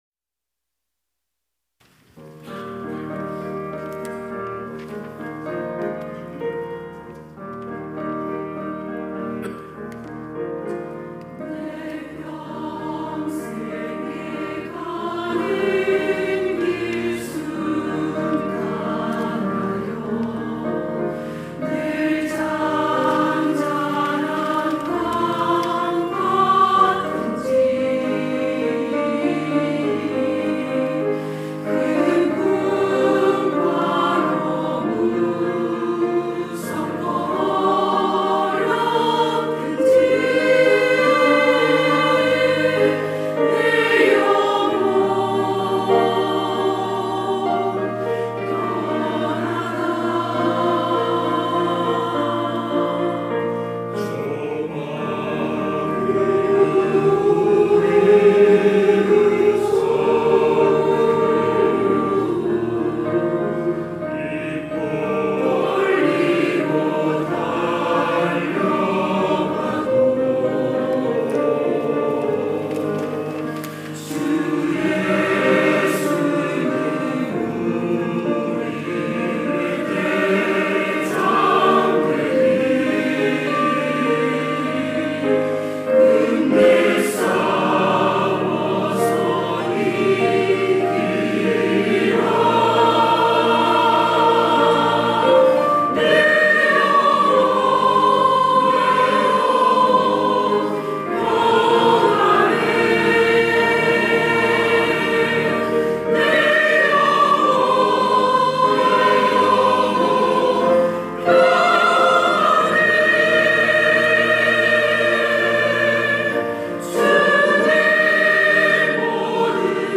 천안중앙교회
찬양대 가브리엘